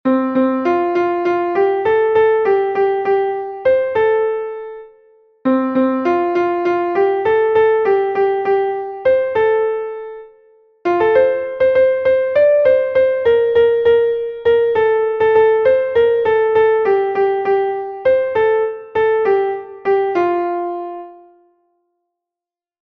Melodie: Volksweise (18. Jahrhundert)